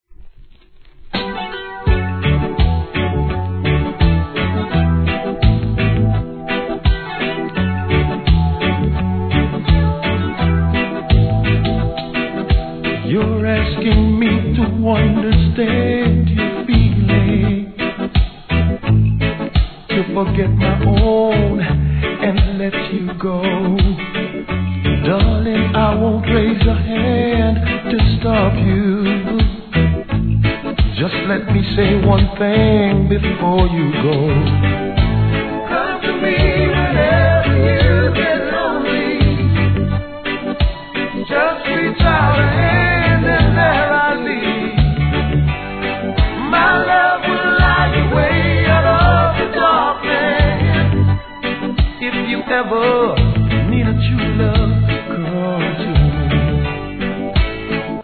REGGAE
ベテラン・シンガーさすがのLOVE SONG♪